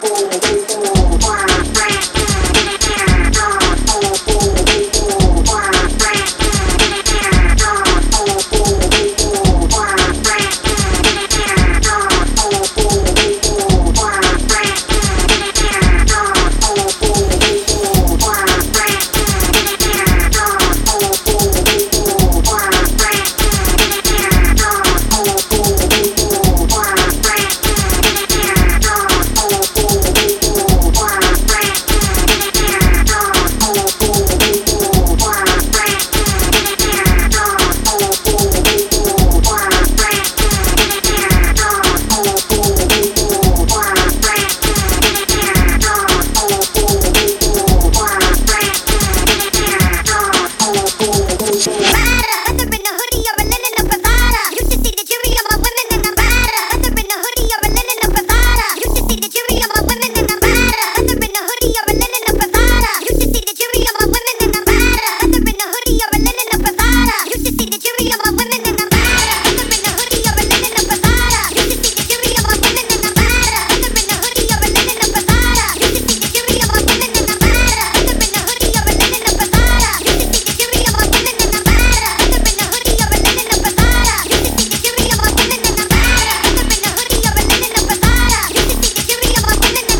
low-slung party starters